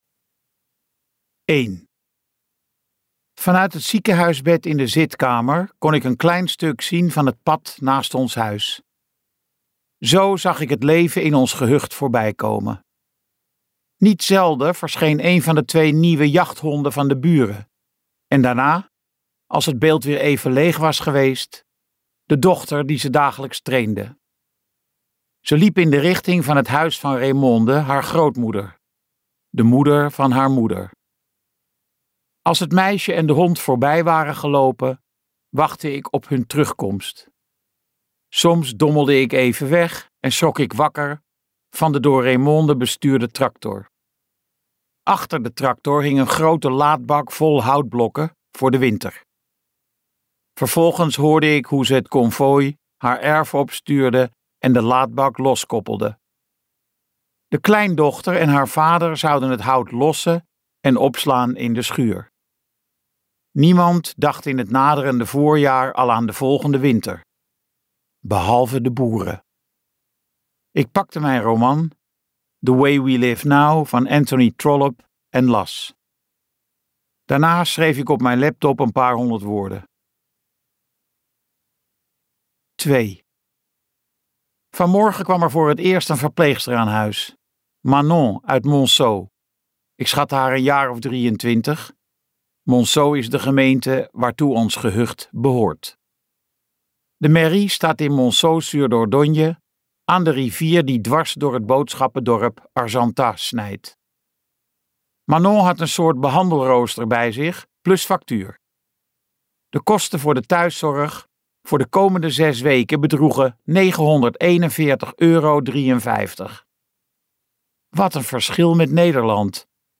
Ambo|Anthos uitgevers - Knieval luisterboek